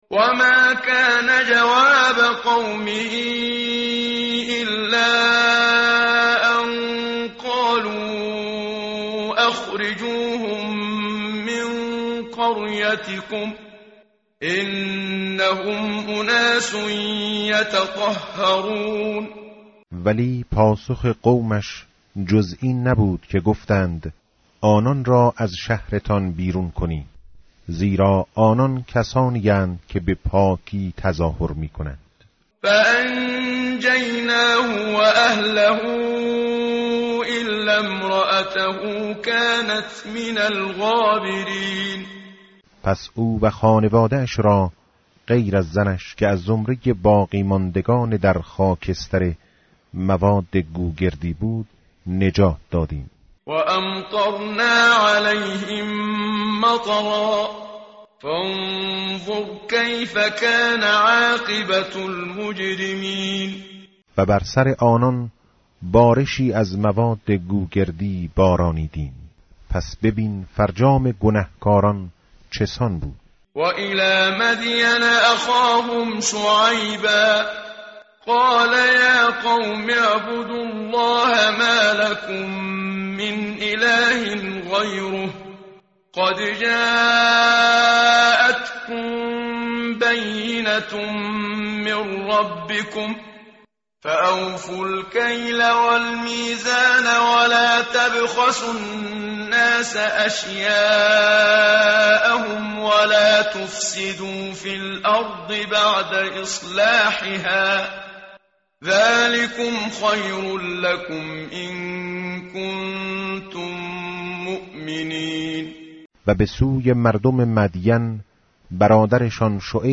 متن قرآن همراه باتلاوت قرآن و ترجمه
tartil_menshavi va tarjome_Page_161.mp3